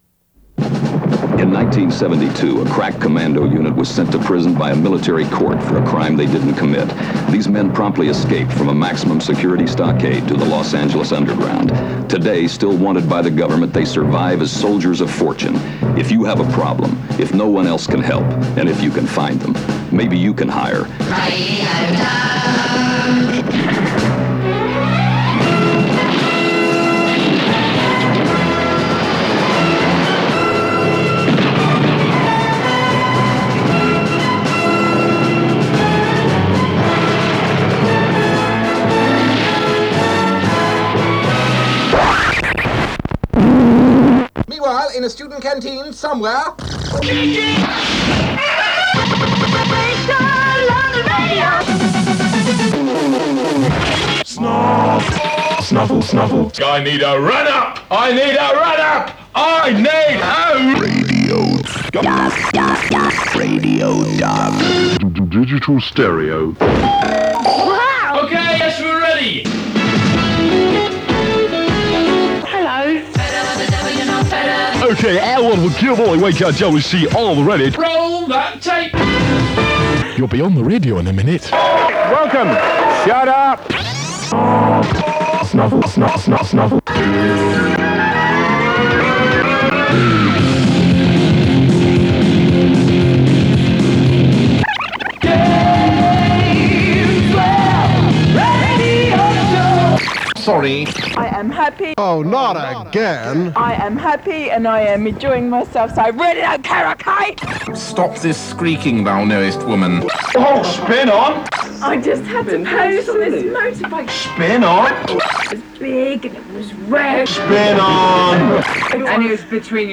10 minutes of split-millisecond precision pause button punching, this is 'Meanwhile in a Student Canteen, Somewhere...'. It's an early Richcolour Show test transmission, recorded in late 1990 on to an Ampex 472 cassette, deep inside the Pioneer CT-F2121.